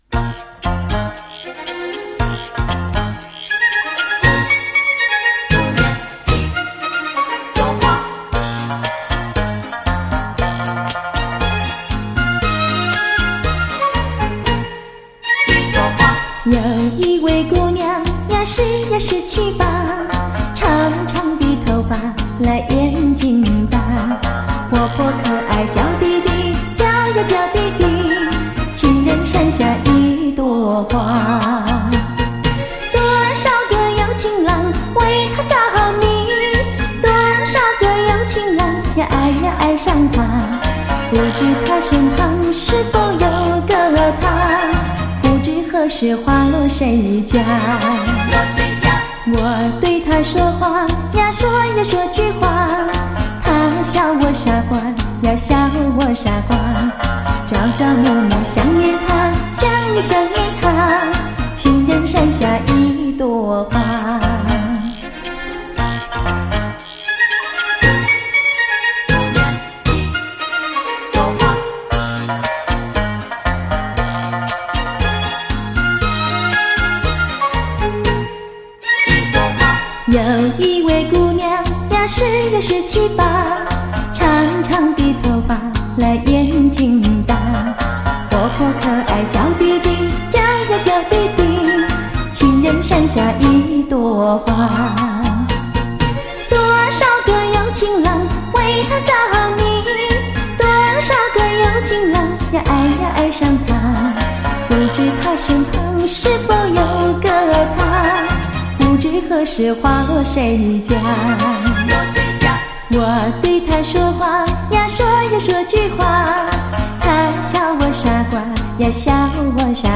婉约深情·款款流露